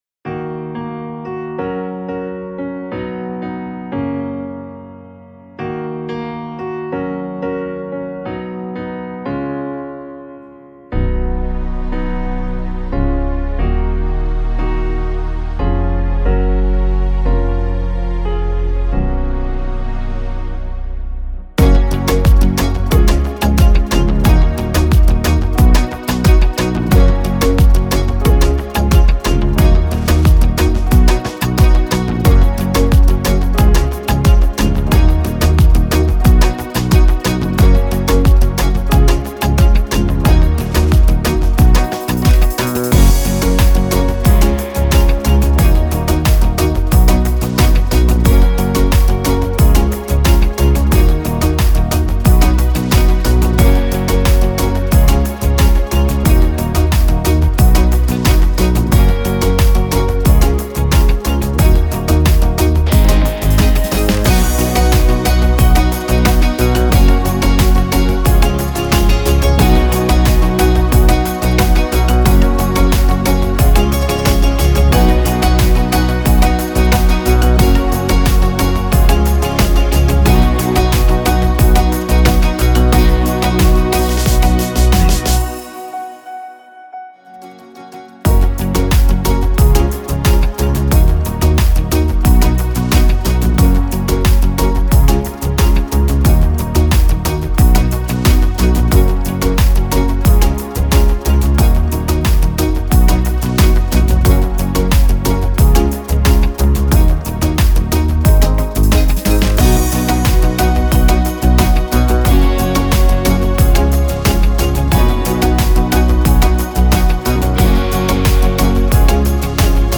Mp3 pesnička zahraná so štýlom z Korg Pa4X